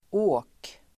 Uttal: [²'å:r_skur_s:]